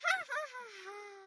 Звуки насмешки
Звук детского издевательского смеха